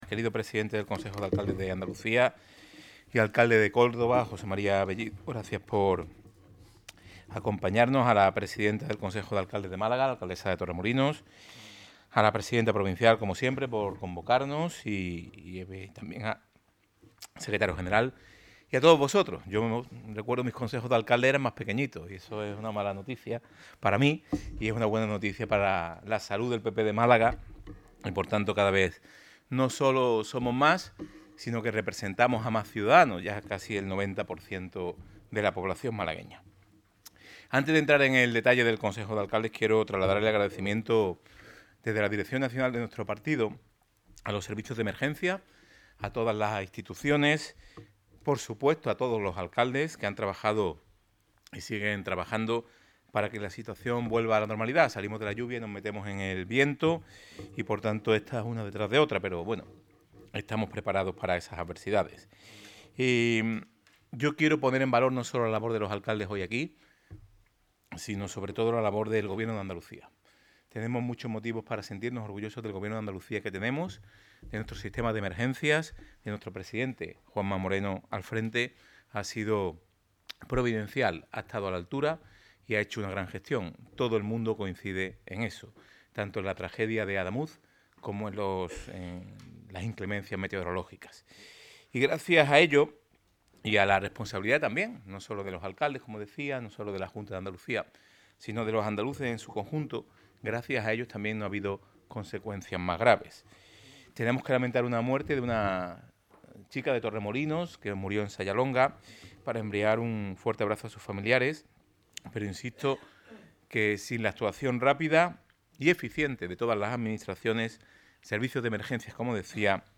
Elias-Bendodo_Consejo-de-alcaldes-14-febrero.MP3.mp3